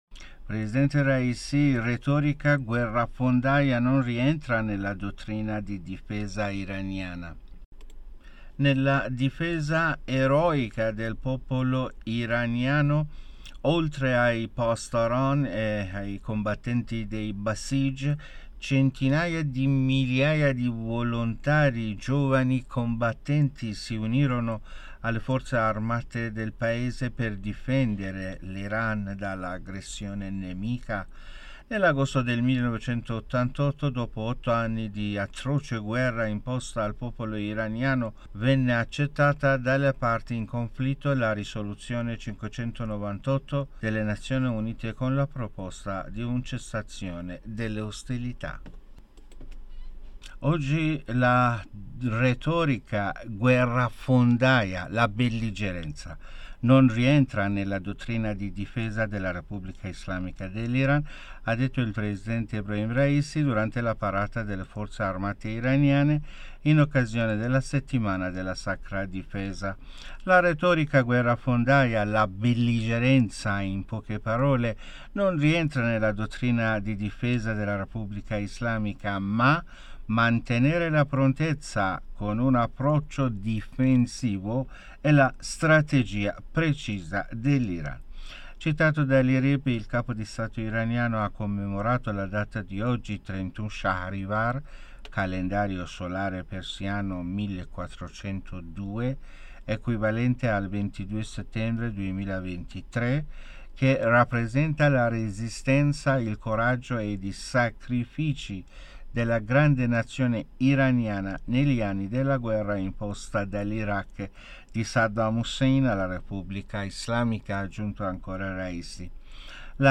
Notiziario